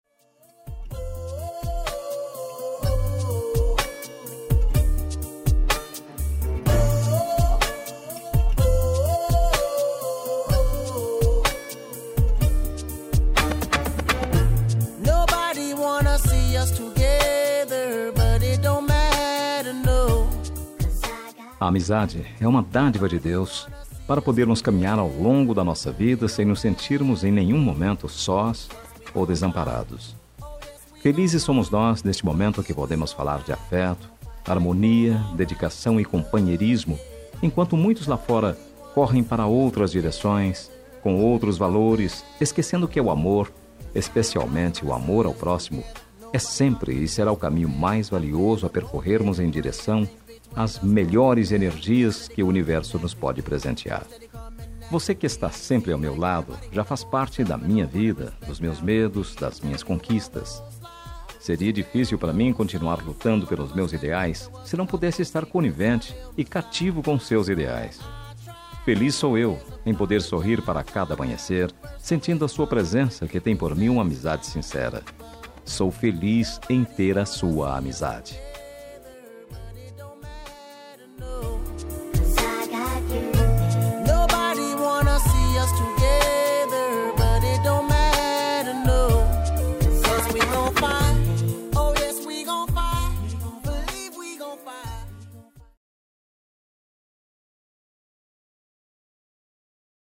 Telemensagem Amizade – Voz Masculina – Cód: 02321